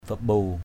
/ba-ɓu:/ (đg.) phơi = sécher, faire sécher. bambu aw bO~% a| phơi áo = faire sécher les habits. bambu ralaow bO~% r_l<| phơi thịt = faire sécher la viande.